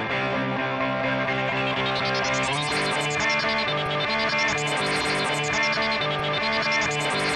Synth2.wav